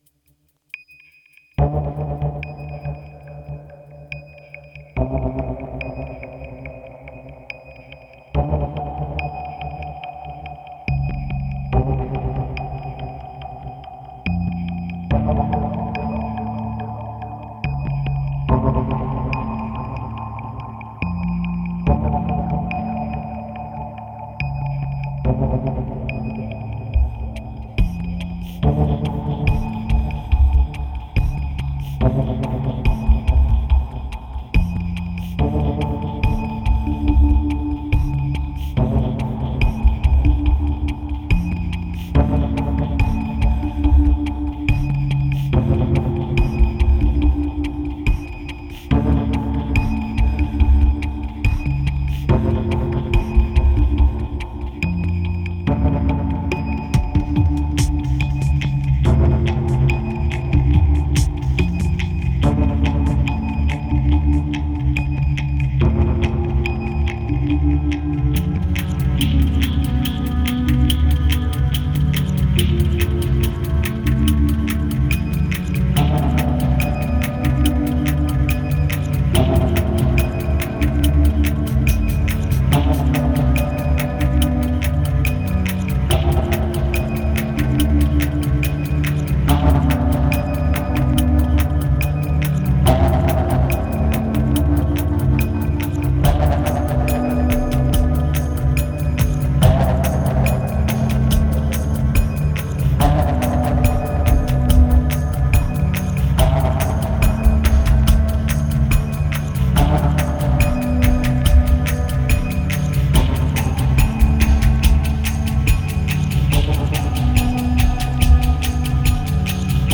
1774📈 - 25%🤔 - 71BPM🔊 - 2013-06-29📅 - -24🌟